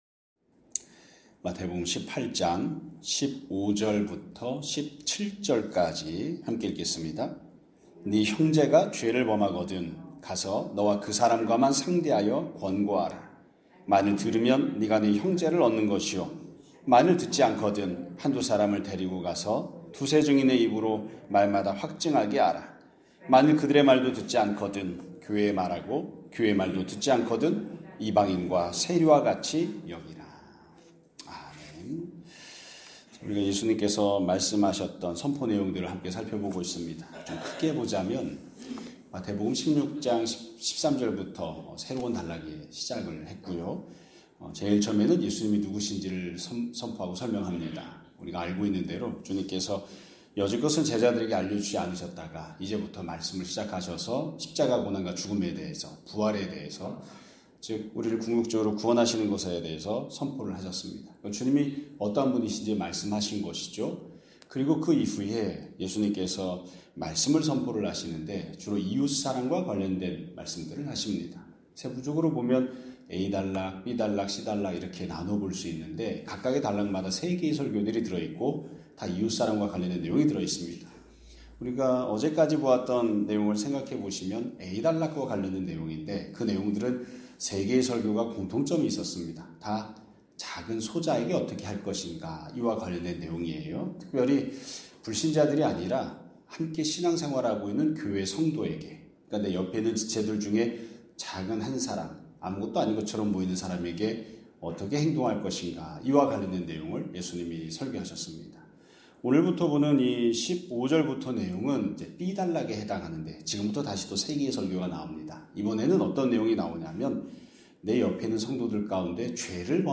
2025년 12월 10일 (수요일) <아침예배> 설교입니다.